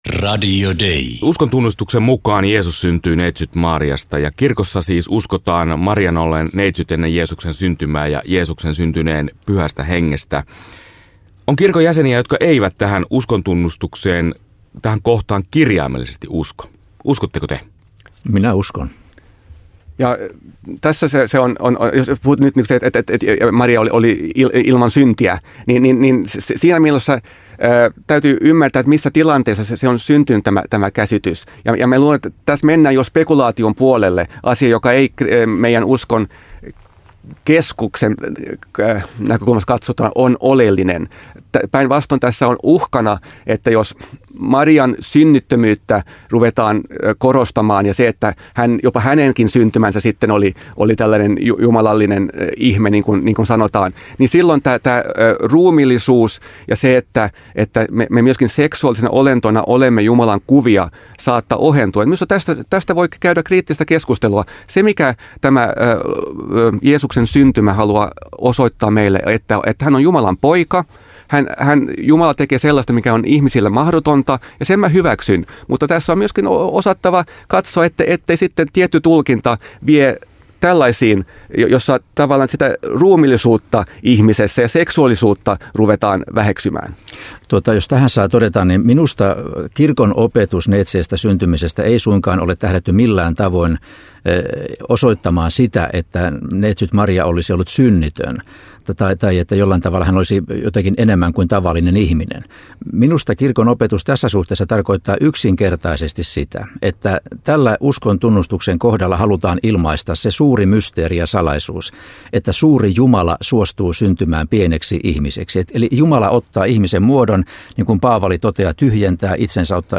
Arkkipiispanvaalin toisella kierroksella kohtaavat kandidaatit Espoon piispa Tapio Luoma ja Porvoon piispa Björn Vikström vastasivat Radio Dein vaalitentissä eri tavoin Jeesuksen neitseestäsyntymistä koskevaan kysymykseen.